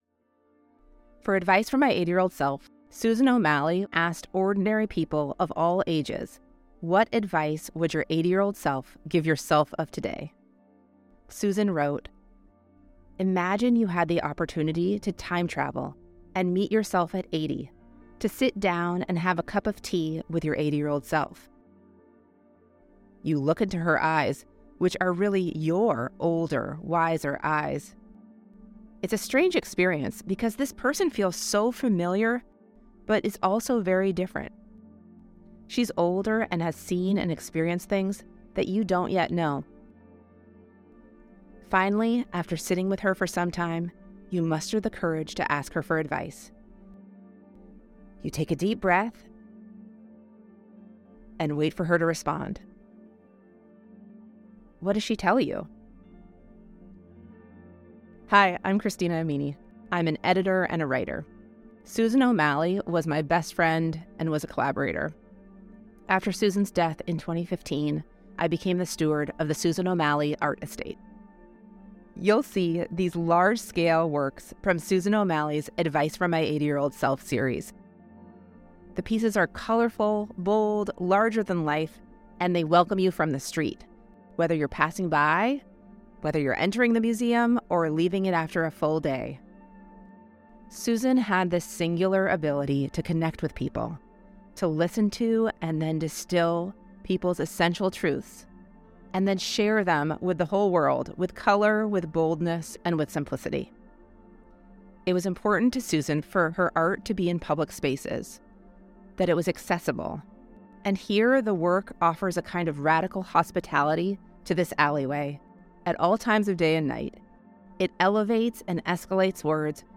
Note: This audio guide features moments from Finding Your Center, a self-help audio tour produced in collaboration with Montalvo Arts Center for the Bay Area Now 7 exhibit, hosted at Yerba Buena Center for the Arts.